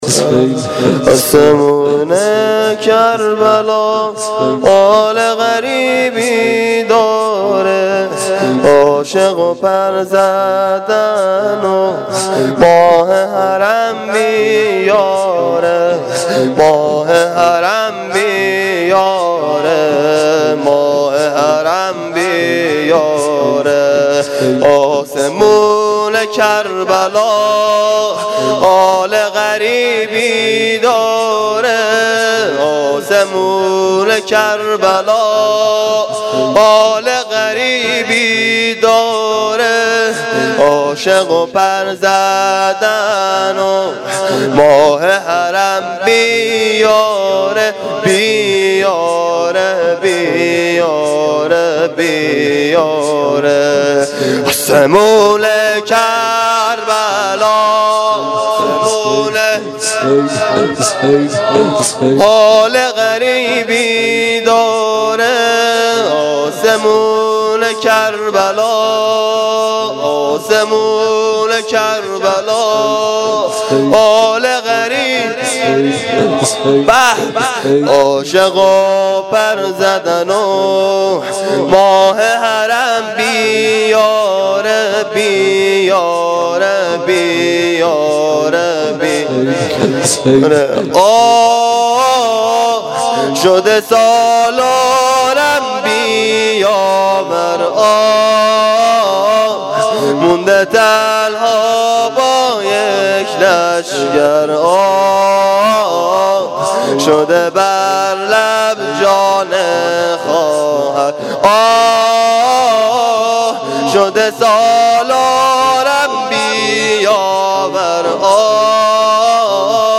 اجرا شده در هیئت جواد الائمه (علیه السلام) کمیجان